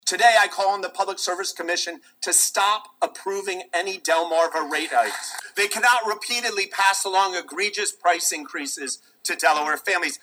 Also, in His State of the State address, The Governor called on the Public Service Commission emphatically telling them to halt approval of additional utility rate hikes.